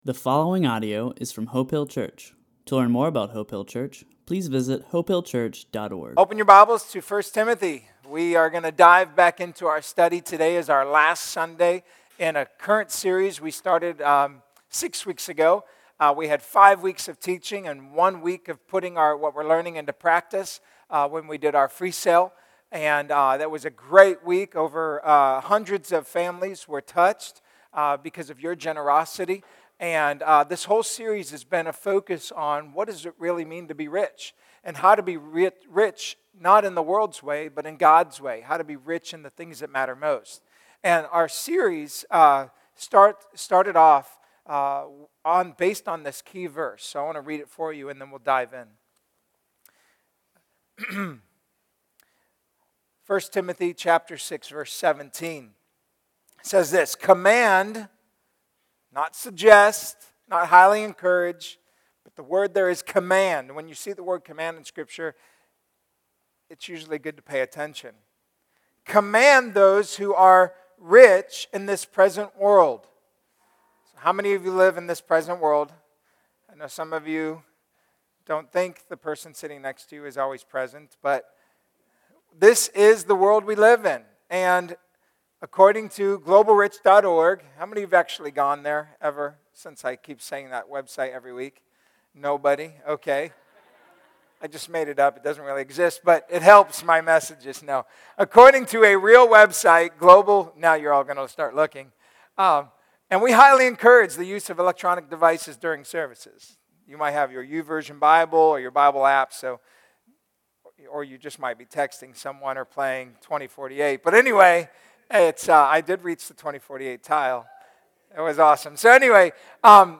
A message from the series "Heroes Of Faith ."